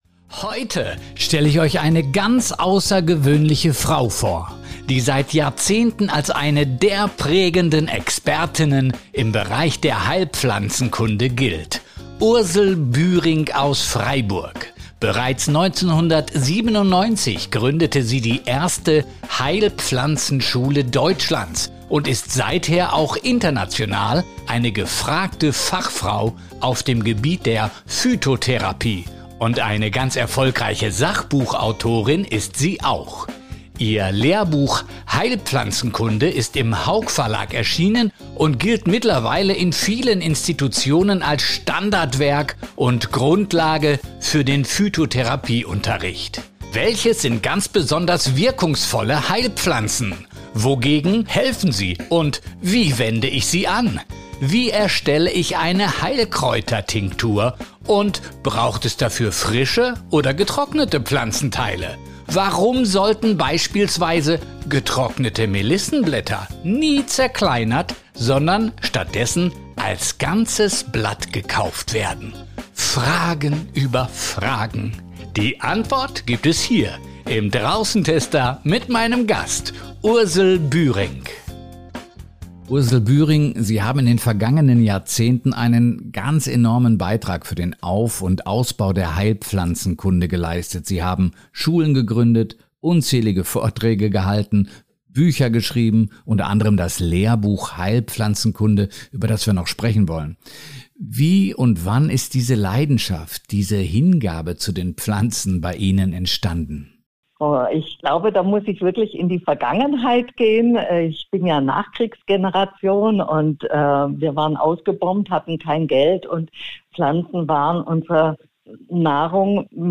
In Teil 1 unseres Interviews reflektiert sie über ihre Faszination für Heilpflanzen, die Verbindung zwischen evidenz- und erfahrungsbasierter Medizin, den Weg hin zur seriösen Quellenbeschaffung (angesichts des grossen Angebots im world wide web) und gibt eine Auswahl von wirkungsvollen Heilkräutern, die jede/r in der eigenen Hausapotheke haben kann.